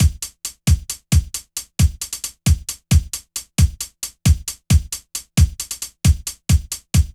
drums04.wav